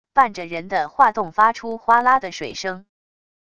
伴着人的划动发出哗啦的水声wav音频